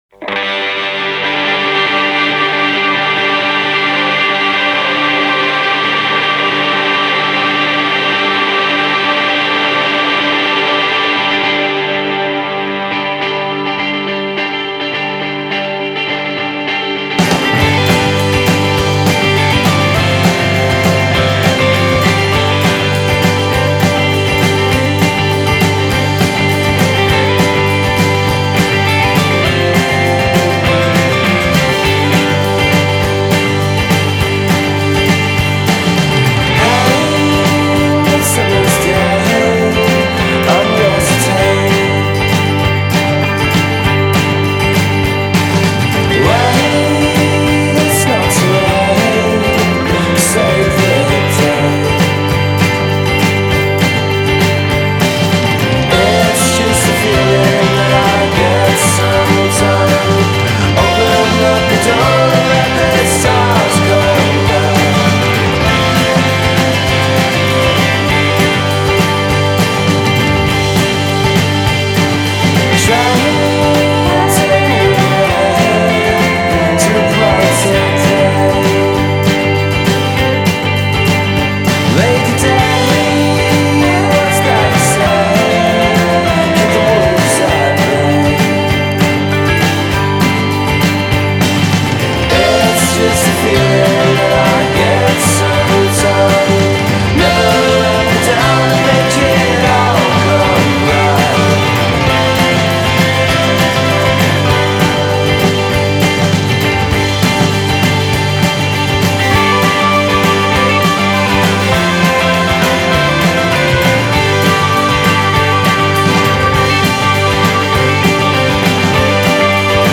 Genre: Indie Pop-Rock / Psychedelic Rock